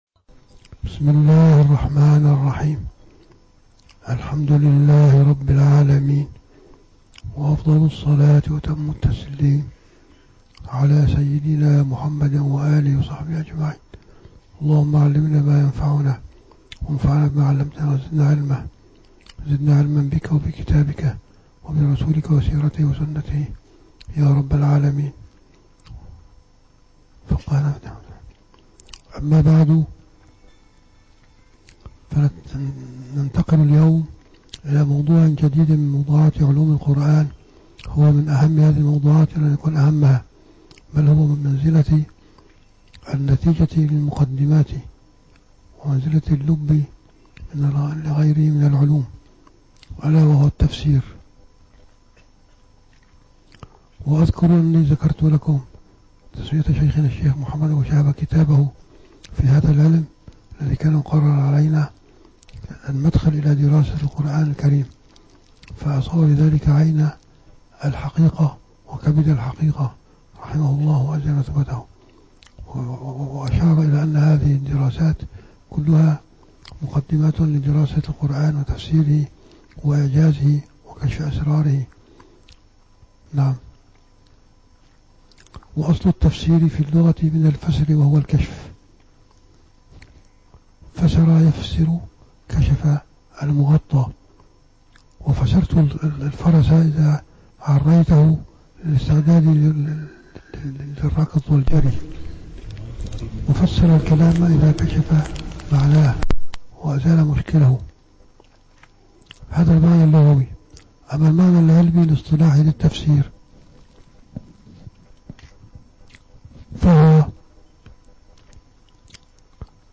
- الدروس العلمية - دورة مختصرة في علوم القرآن الكريم - 5- علوم القرآن الكريم